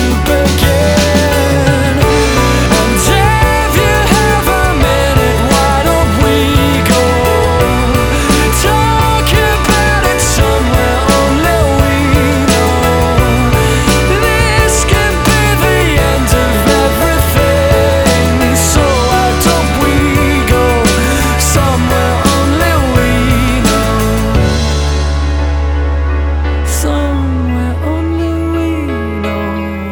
• Rock
alternative rock band